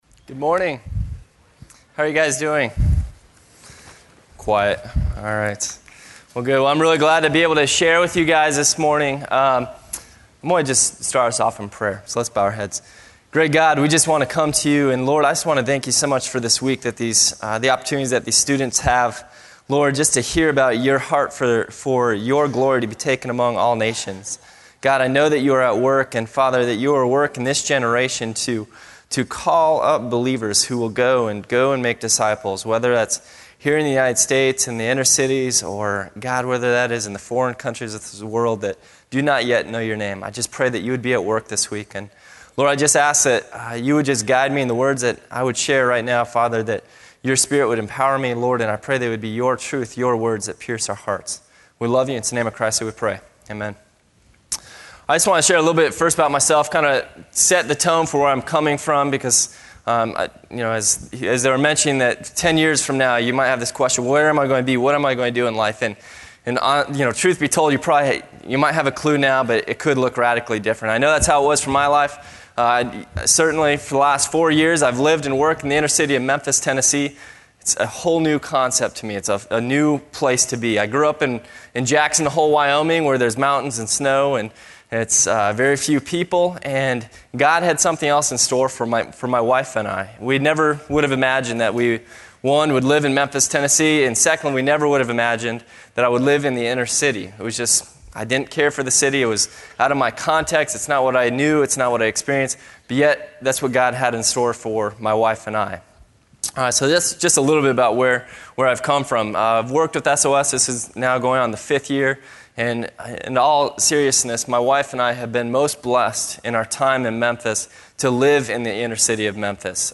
GO Week Chapel: Service Over Self (SOS) Memphis